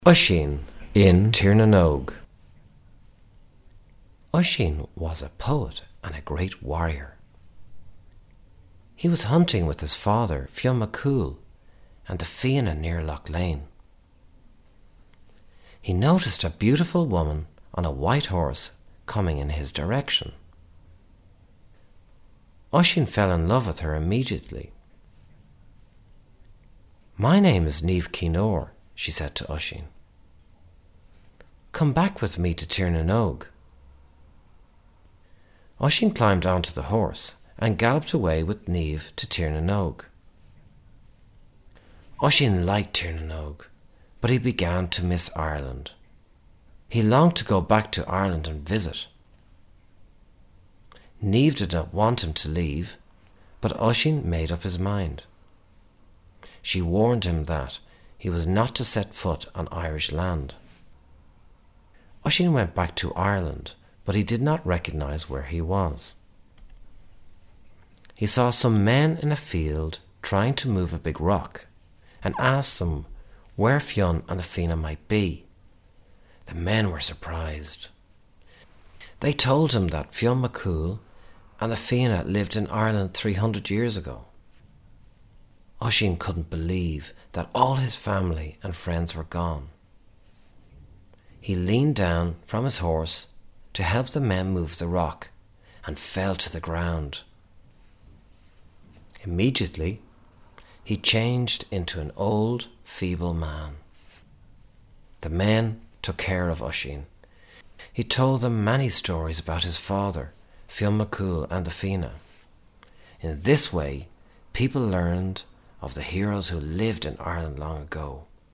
Oisín in Tír na nÓg Audiobook, junior version